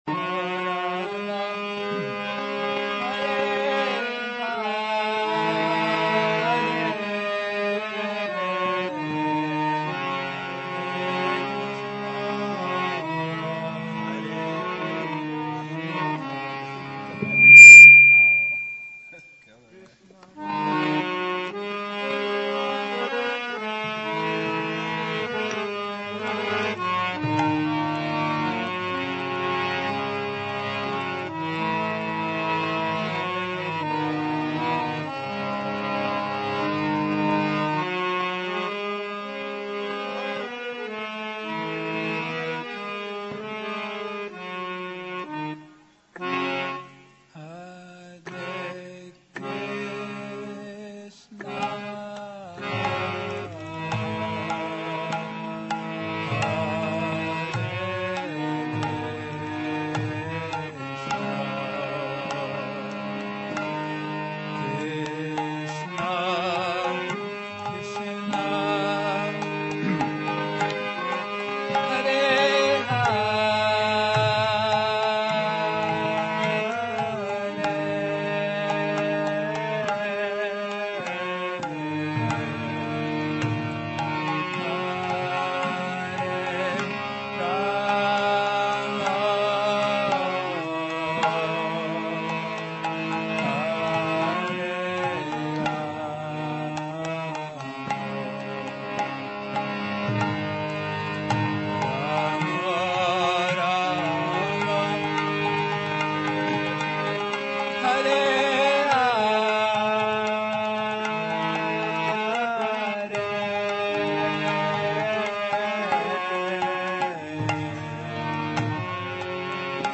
Hare Krsna Kirtana